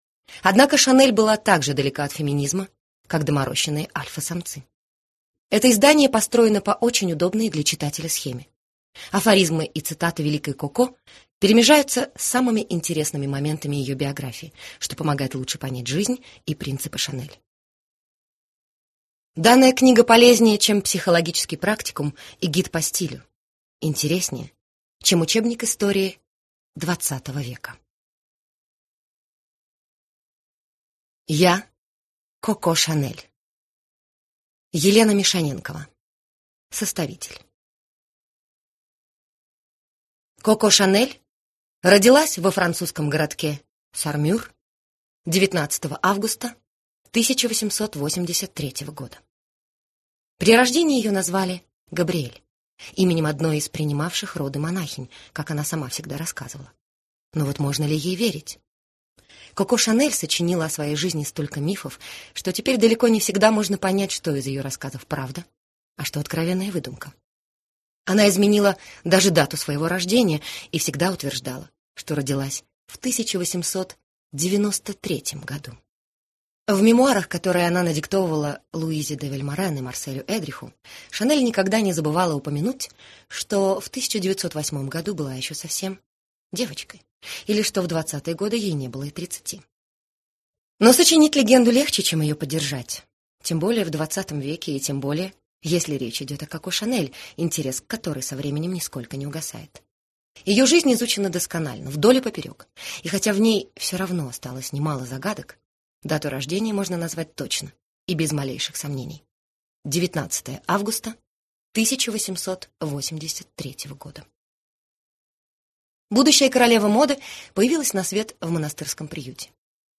Аудиокнига Я – Коко Шанель | Библиотека аудиокниг